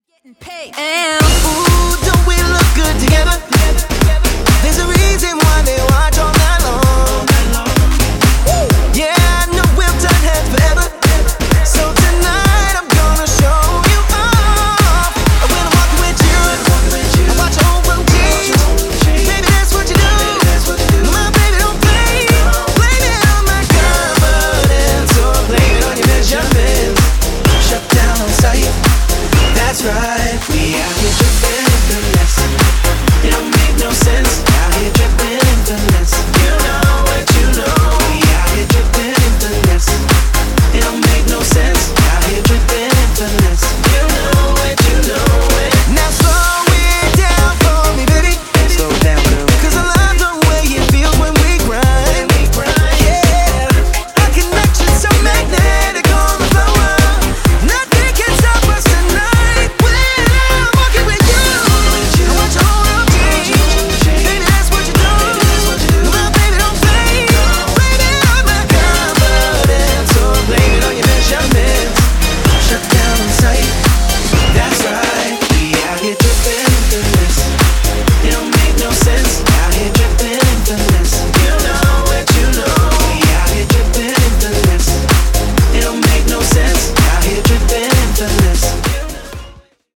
Chansons non chantées par les artistes originaux